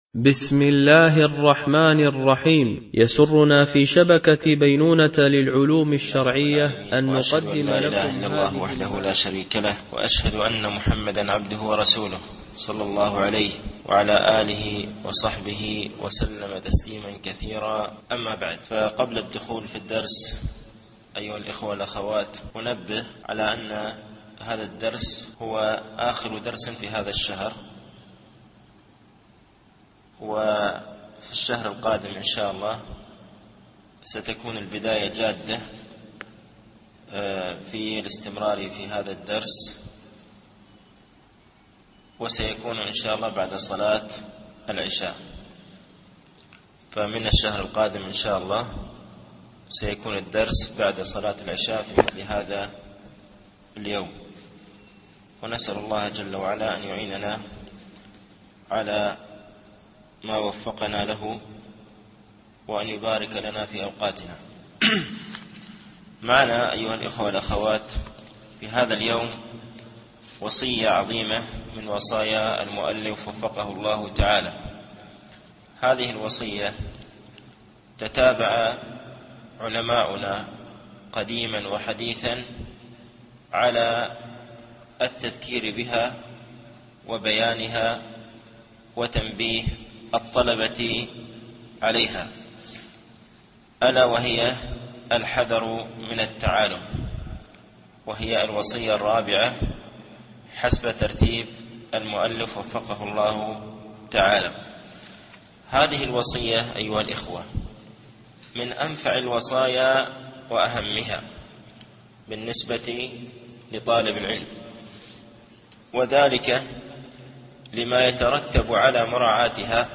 التعليق على كتاب معالم في طريق طلب العلم (وصية4موقف طالب العلم من التعالم1) - الدرس الحادي و الأربعون
MP3 Mono 22kHz 32Kbps (CBR)